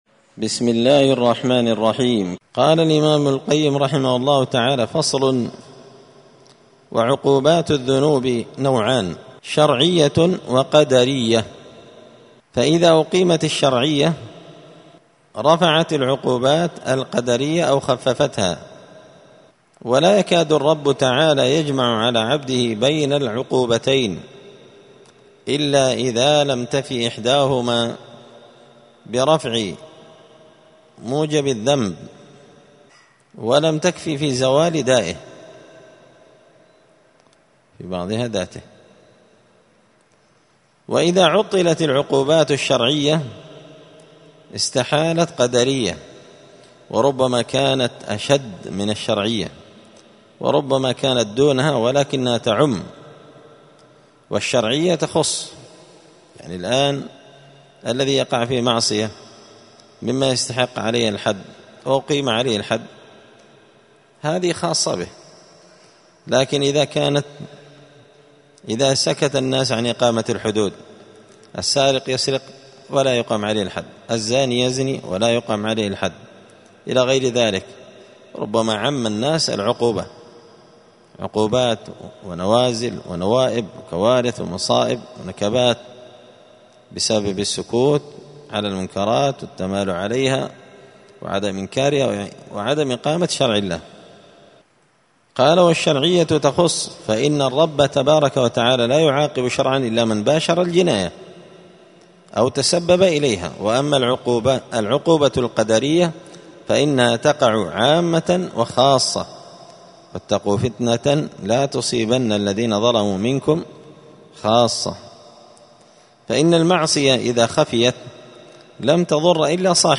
*الدرس الخمسون (50) فصل وعقوبات الذنوب نوعان شرعية وقدرية*
دار الحديث السلفية بمسجد الفرقان بقشن المهرة اليمن 📌الدروس الأسبوعية